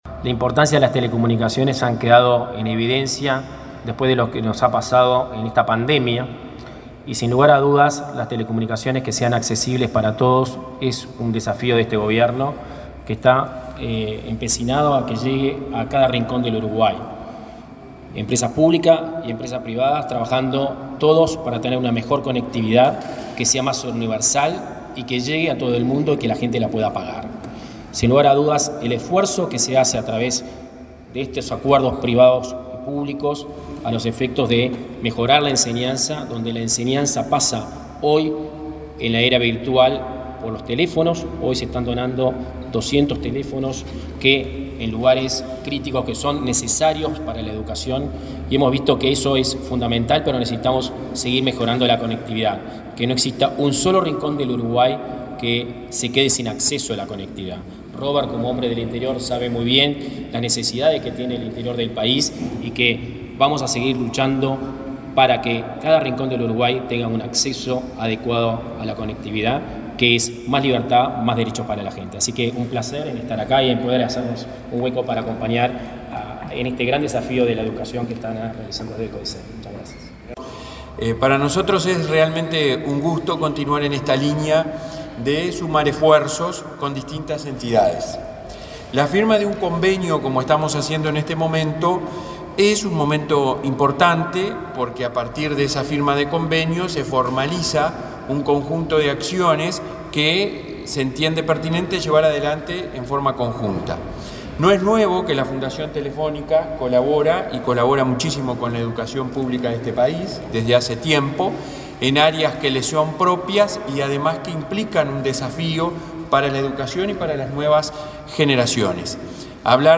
Palabra de autoridades en firma de convenio entre ANEP y Fundación Movistar
El director nacional de Telecomunicaciones, Guzmán Acosta y Lara, y el presidente de la Administración Nacional de Educación Pública (ANEP), Robert Silva, participaron este martes 24 en la firma de un convenio entre ANEP y la Fundación Telefónica Movistar,para impulsar proyectos que impliquen la incorporación de tecnología en el aula.